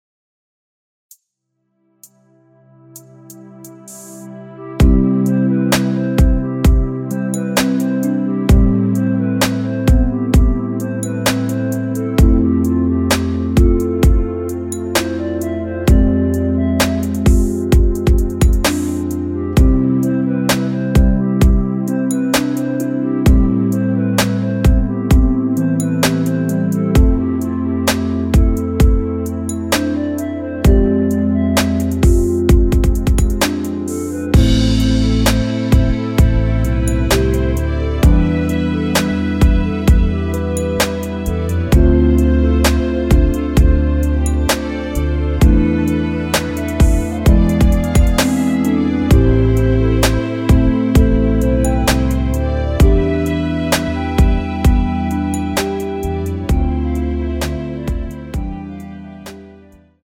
원키에서(+1)올린 멜로디 포함된 MR 입니다.
앞부분30초, 뒷부분30초씩 편집해서 올려 드리고 있습니다.